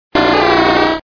Cri d'Otaria dans Pokémon Diamant et Perle.